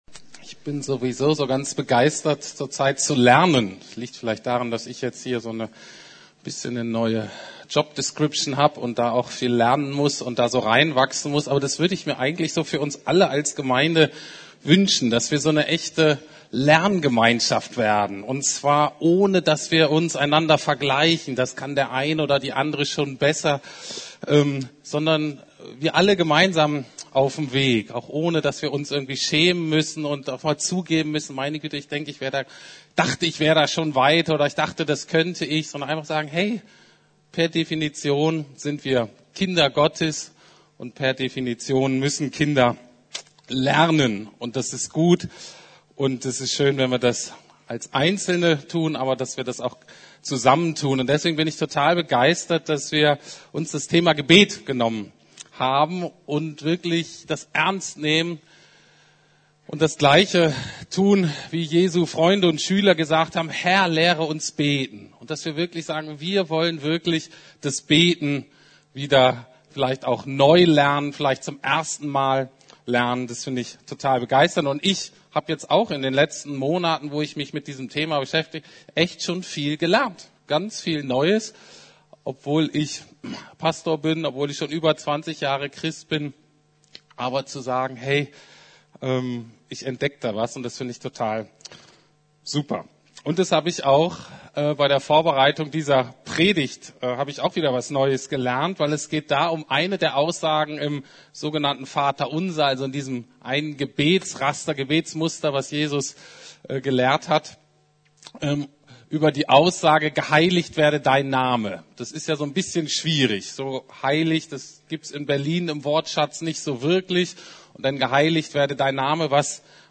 Geheiligt werde Dein Name! ~ Predigten der LUKAS GEMEINDE Podcast